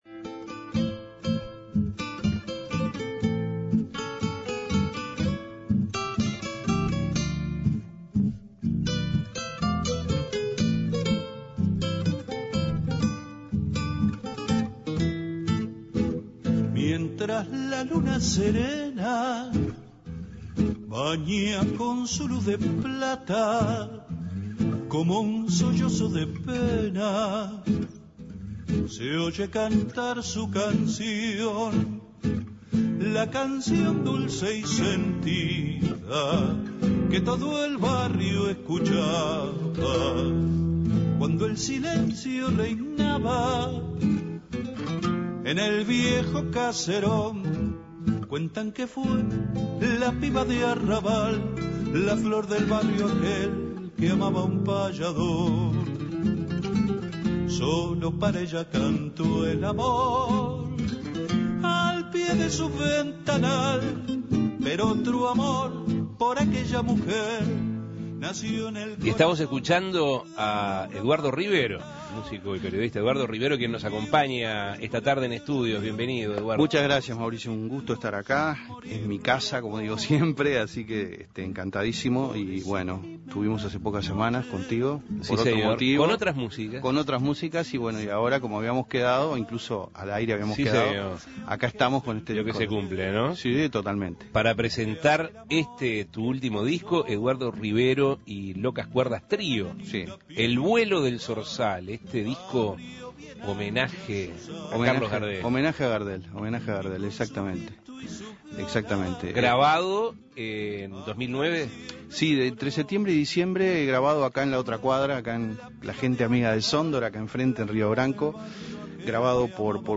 Comentó cómo y por qué surgió este disco, cuánto tiempo le llevó prepararlo y grabarlo, el origen del nombre, dónde y cuándo lo estará presentando, y cómo fue la incorporación de Locas Cuerdas Trío. Escuche la entrevista.